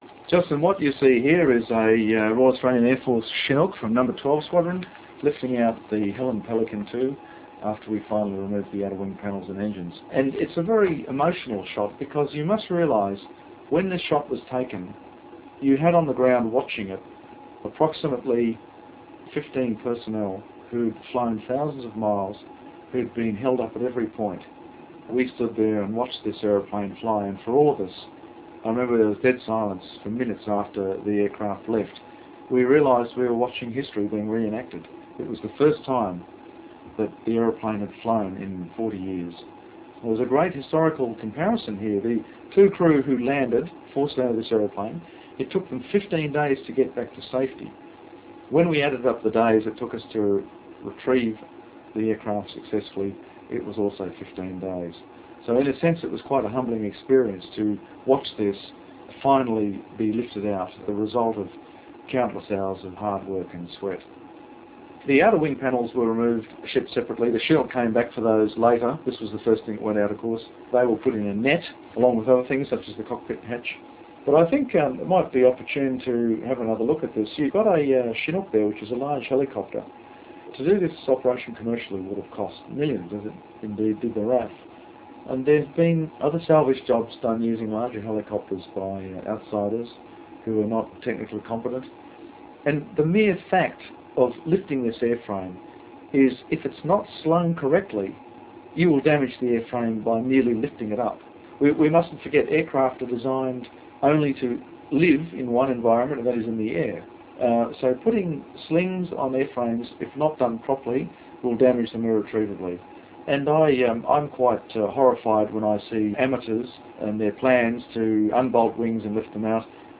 Pacific Ghosts - Audio Commentary
The sample audio below is compressed for streaming via 28.8 modem.